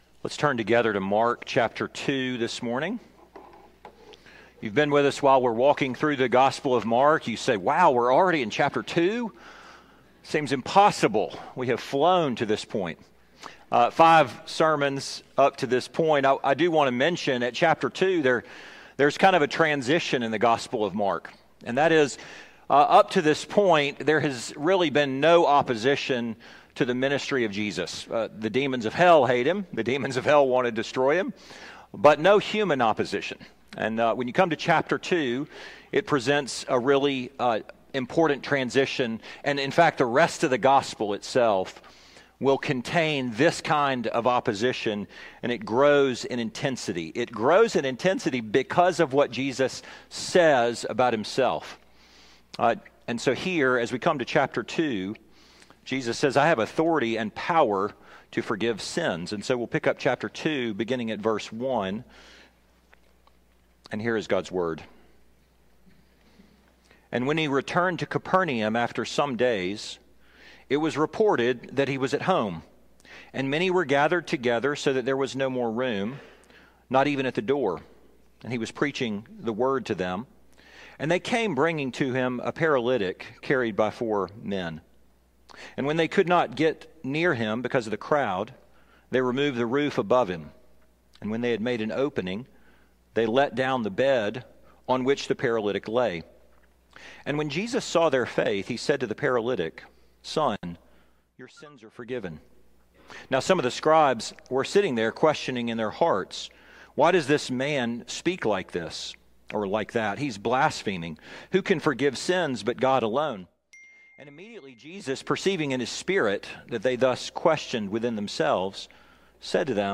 2023 Authority to Forgive Sins Preacher